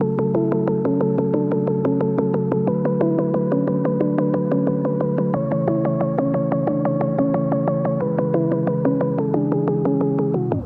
Stem